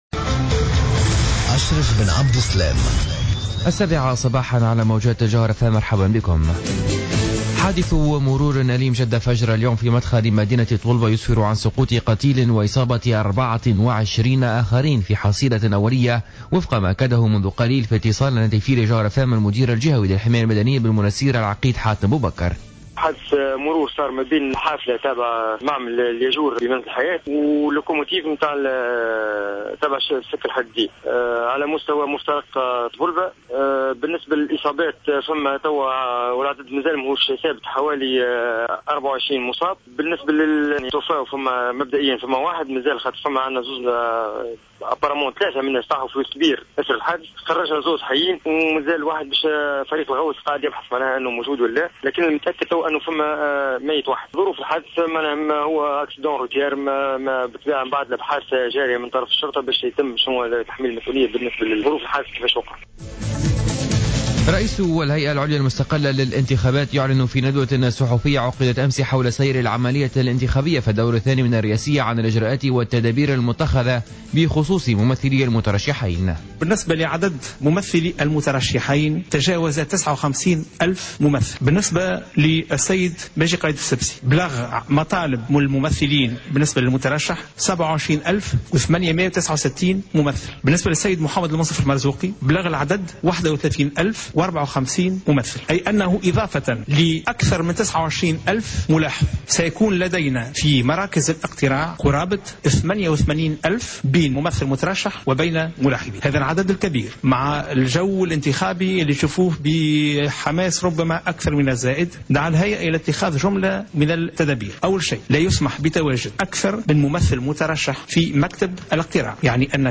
نشرة اخبار السابعة صباحا ليوم الجمعة 19-12-14